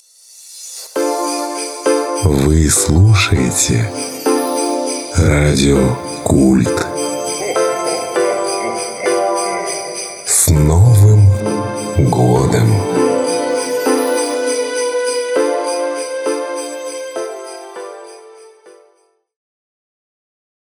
Муж, Рекламный ролик/Средний